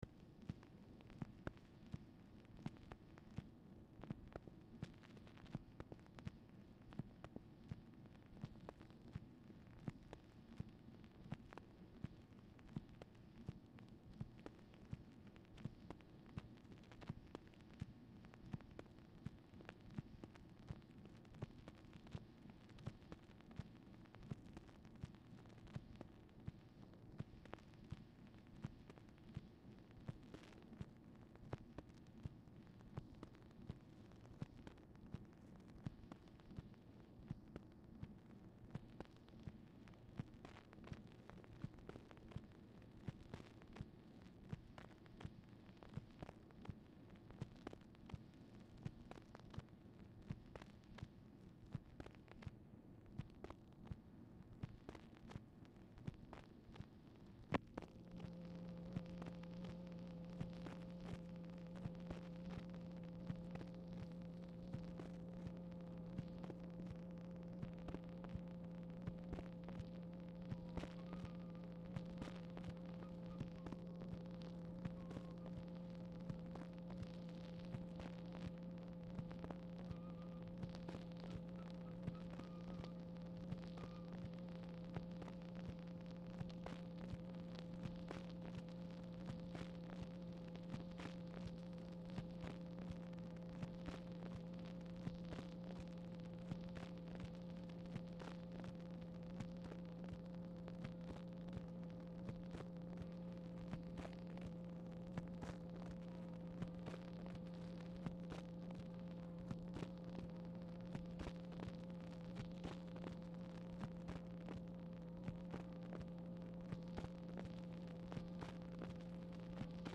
Telephone conversation # 5515, sound recording, OFFICE NOISE, 9/7/1964, time unknown | Discover LBJ
Format Dictation belt